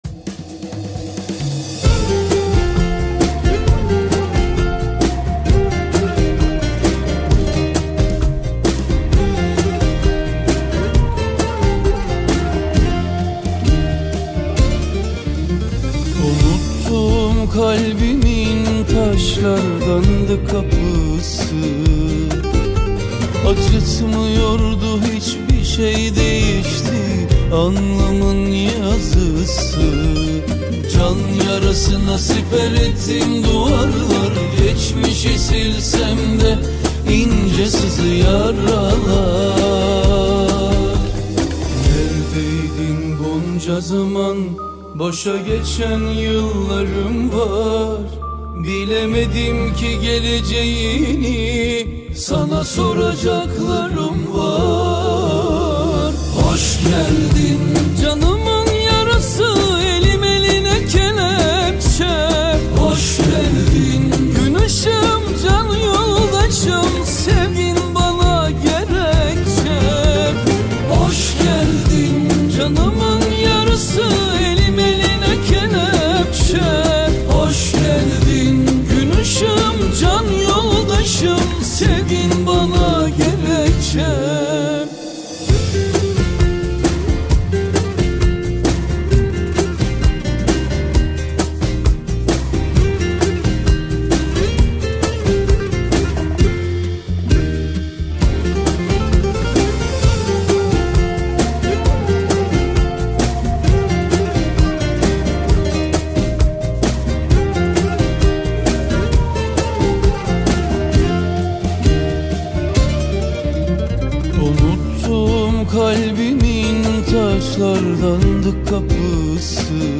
Arabesque, Turkish Folk Music